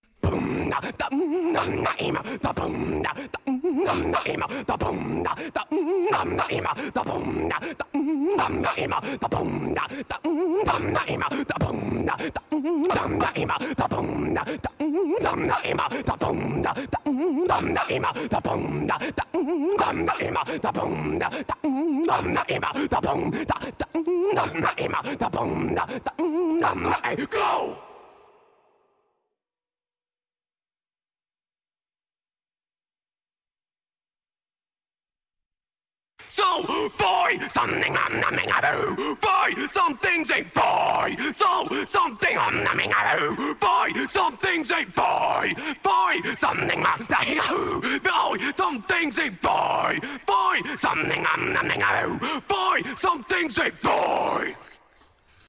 Nu-Metal
Geschrei, Gröhlen und Gesang